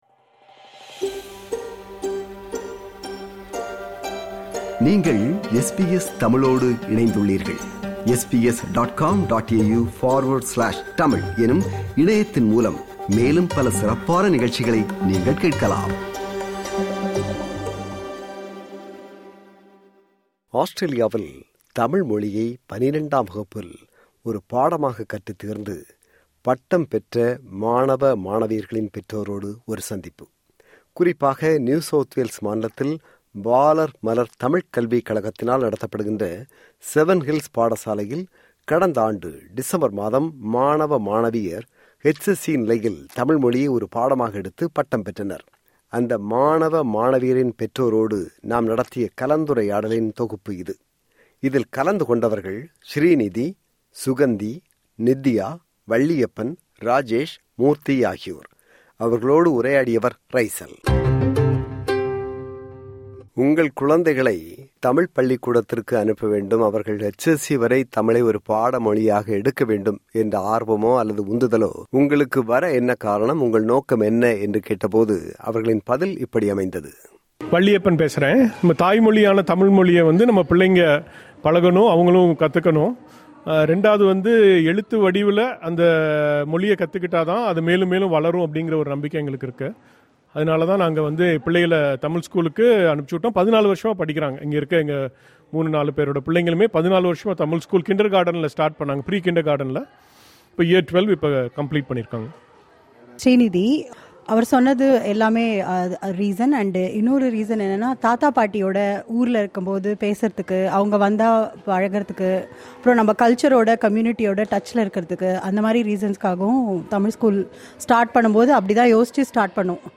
அந்த மாணவ மாணவியரின் பெற்றோரோடு நாம் நடத்திய கலந்துரையாடலின் தொகுப்பு.